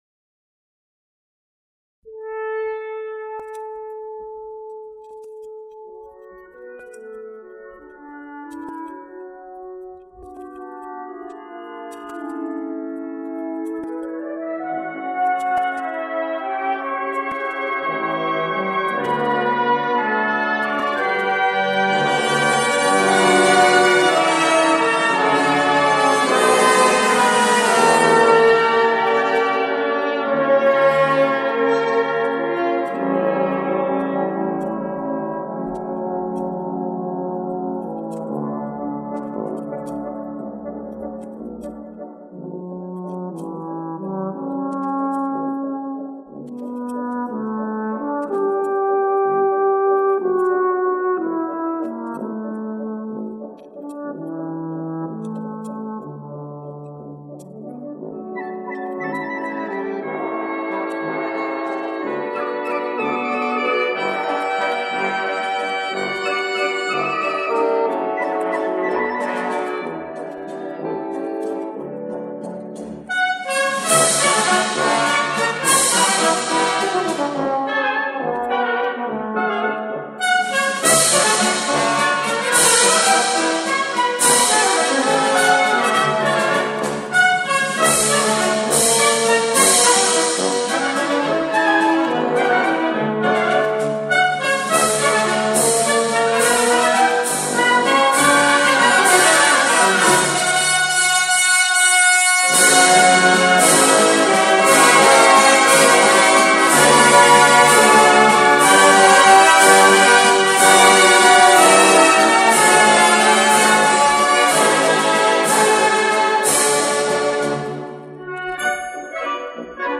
Вторая сторона пластинки